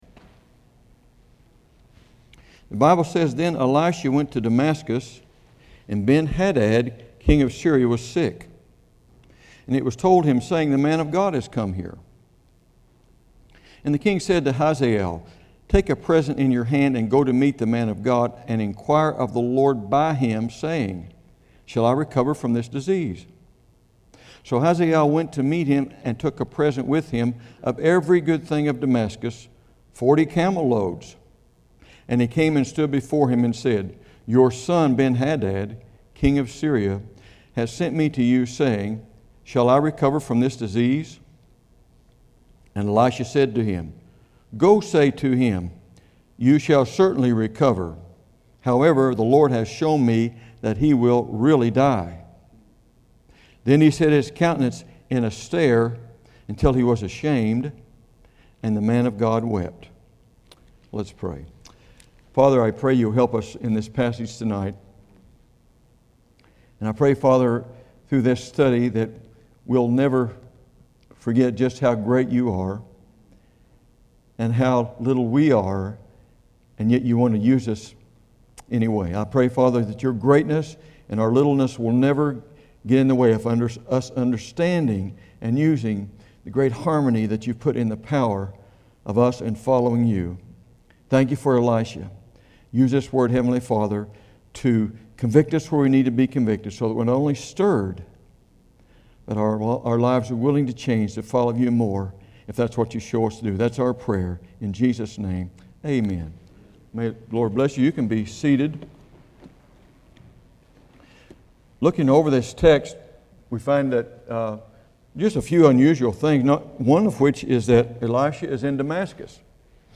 2016 The Good The Bad The Ugly 2 Kings This is an evening sermon with no manuscript attached.